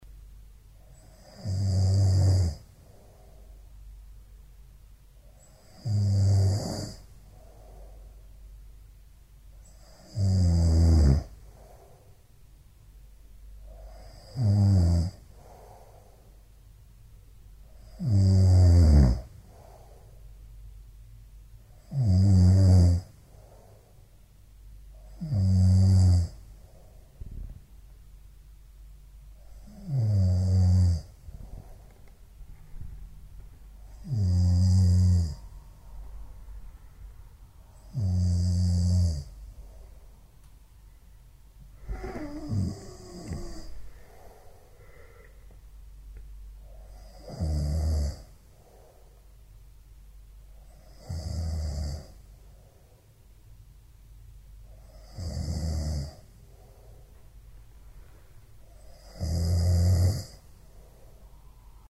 Звуки сопения
Громкое сопение мужчины